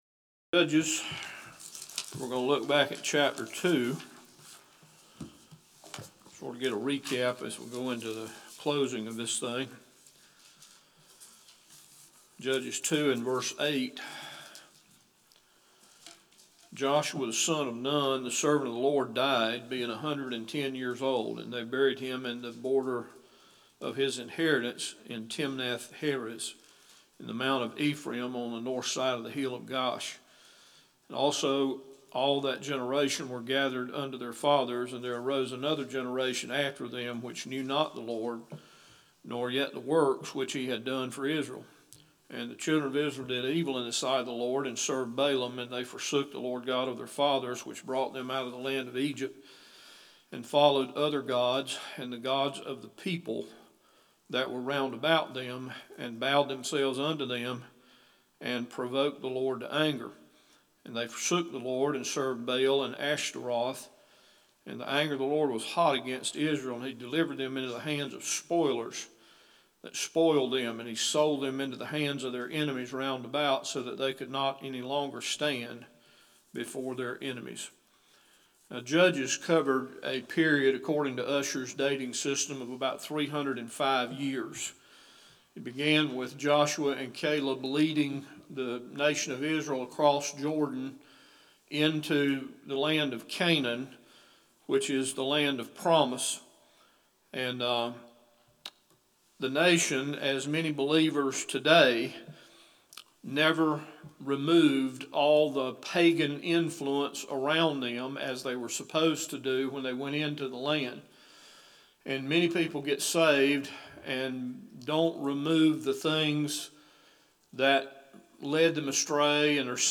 Judges Bible Study 13 – Bible Baptist Church